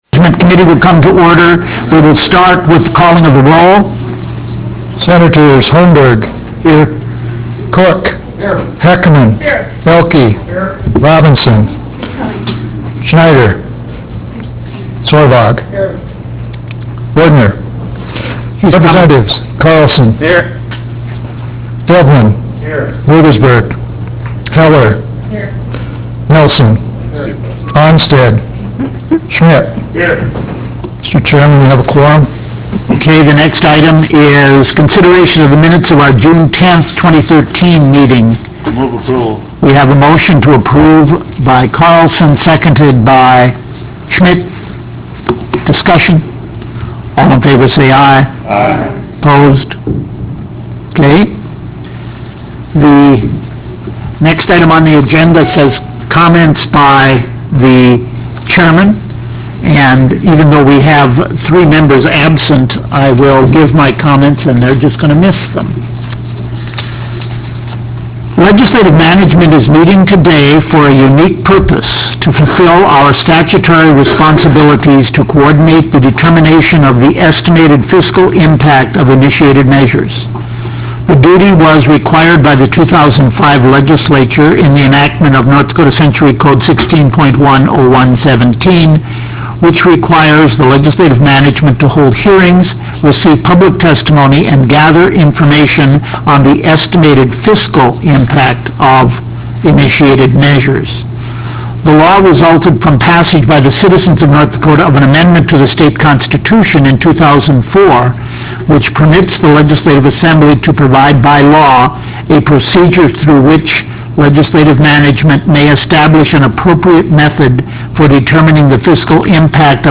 Roughrider Room State Capitol Bismarck, ND United States